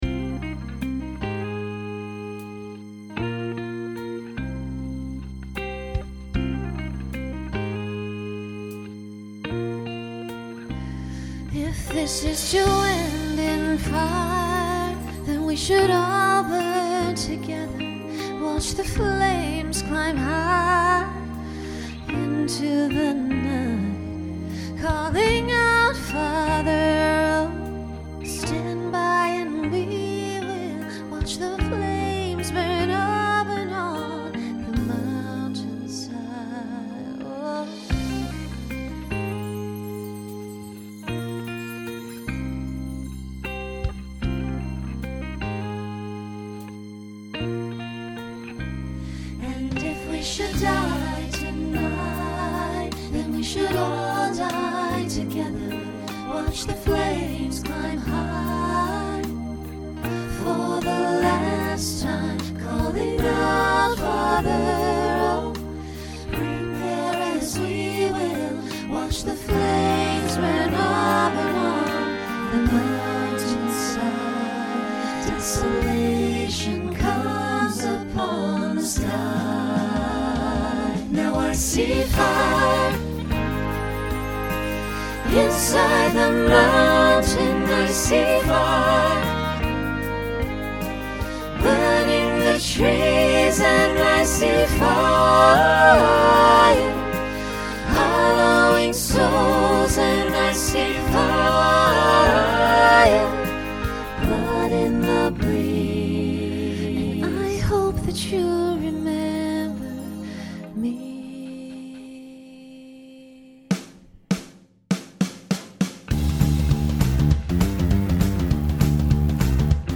Pop/Dance , Rock
Voicing SATB